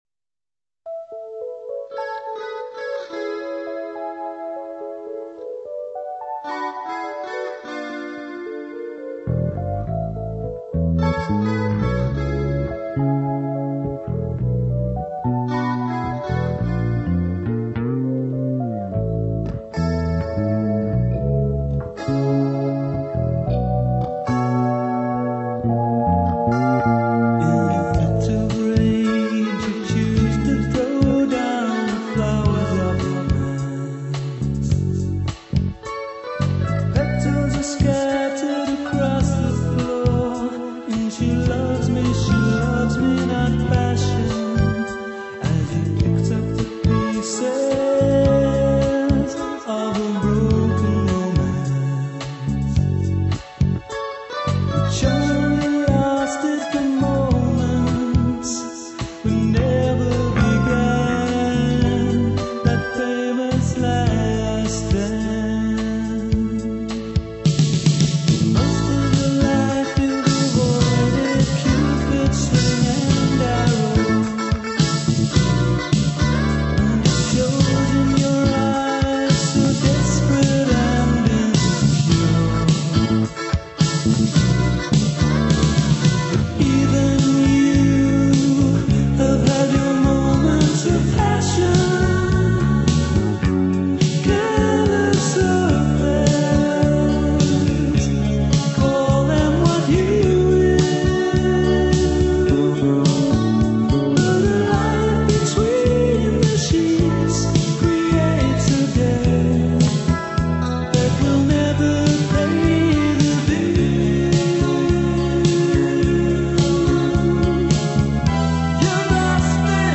started life as a kind of tango.